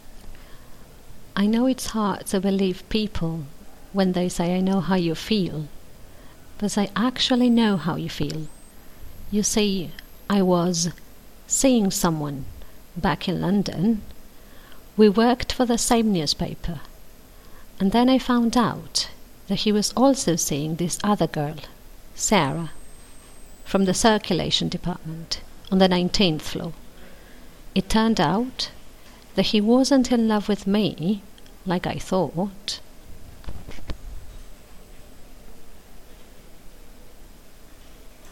I know how you feel Part 1 dictation.mp3